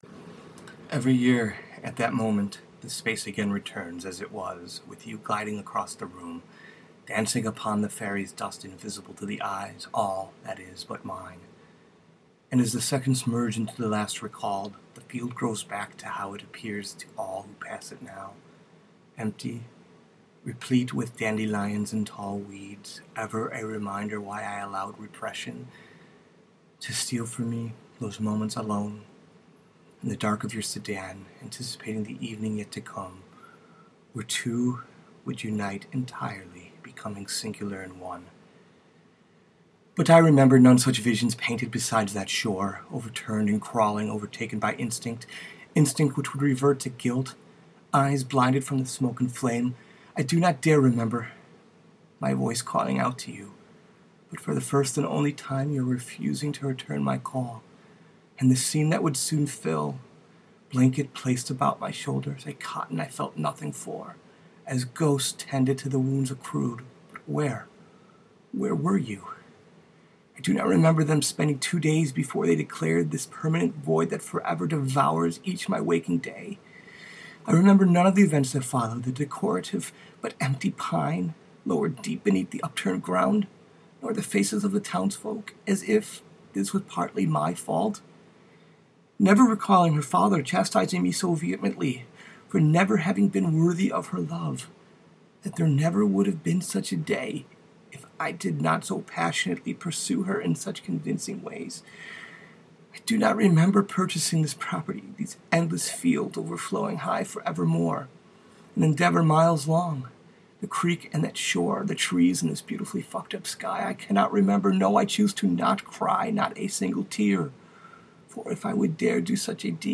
Poetry, lament, delusion, sadness, loss, disbelief, wanting to cling, poem, reading, story